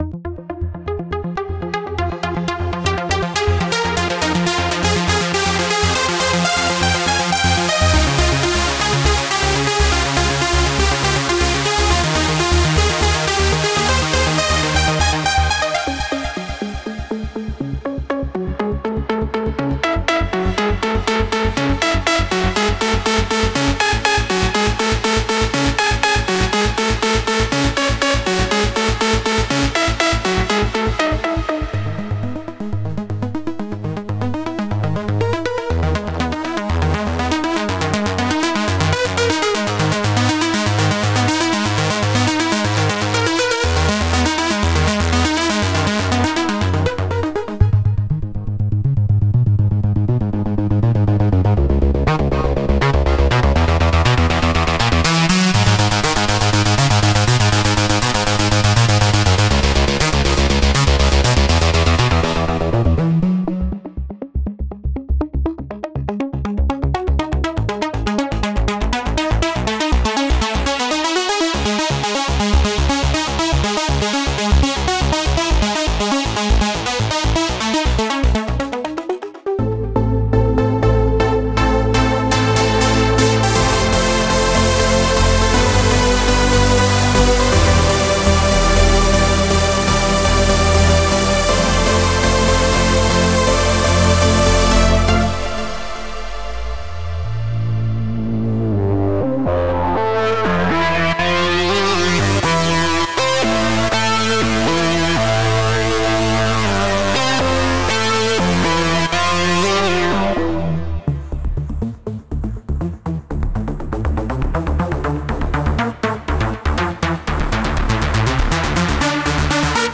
Hardcore / Hardstyle Melodic Techno Multi-genre Techno Trance Uplifting Trance
a brand new Spire presets dedicated to modern trance sounds.
Leads for Spire consists of 20 Lead Presets for Reveal Sound Spire focusing on lead sounds.
The pack also includes 20 Lead MIDI patterns you can hear it in audiodemo.